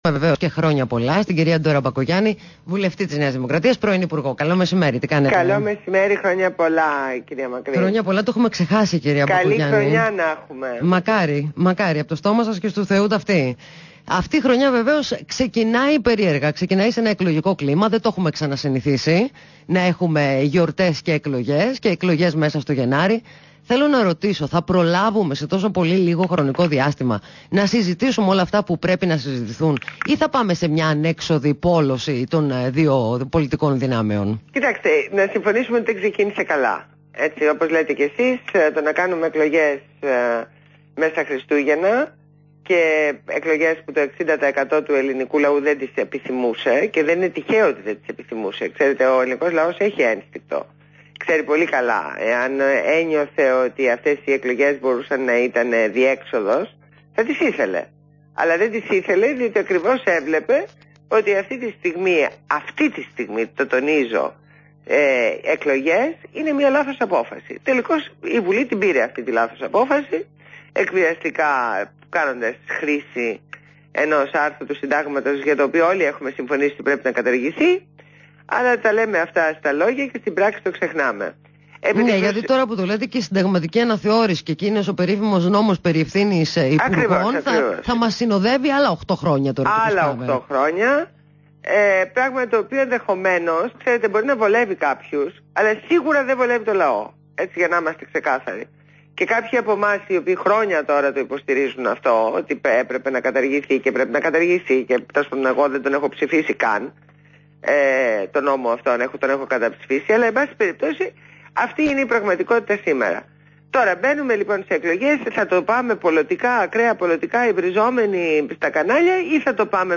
Συνέντευξη στο ραδιόφωνο του REAL FM